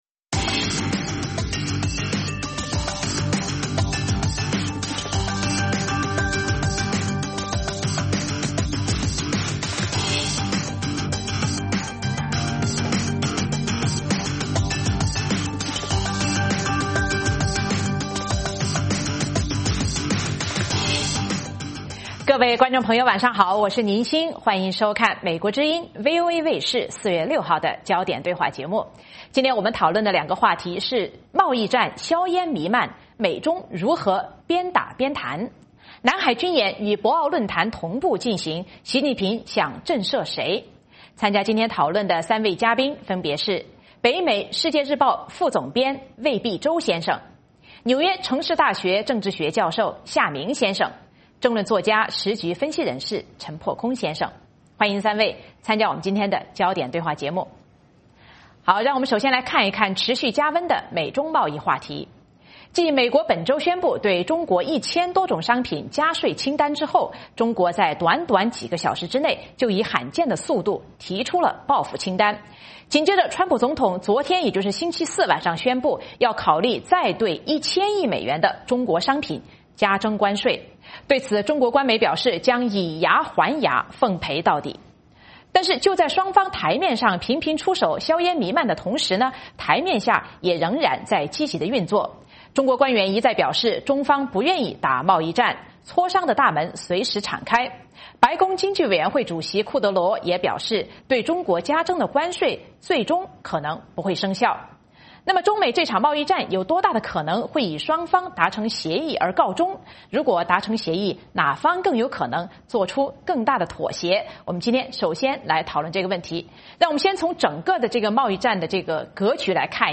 美国之音中文广播于北京时间每周五晚上9-10点播出《焦点对话》节目。《焦点对话》节目追踪国际大事、聚焦时事热点。邀请多位嘉宾对新闻事件进行分析、解读和评论。